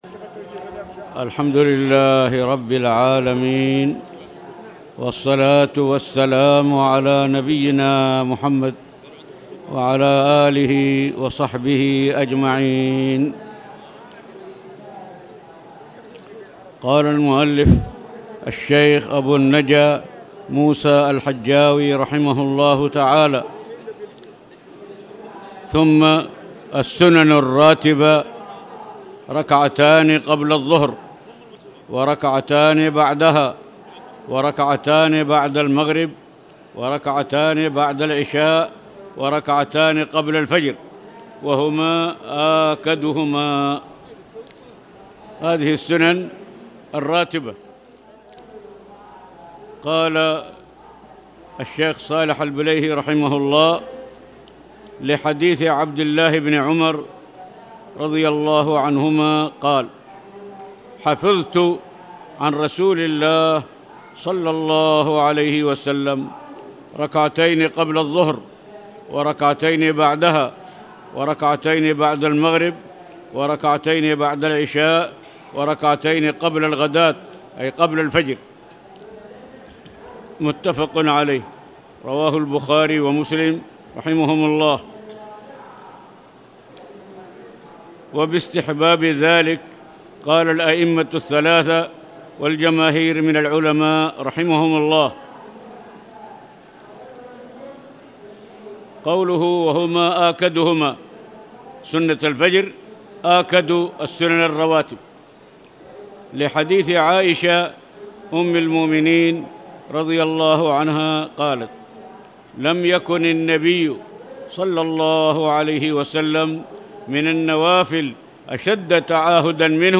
تاريخ النشر ٧ رجب ١٤٣٨ هـ المكان: المسجد الحرام الشيخ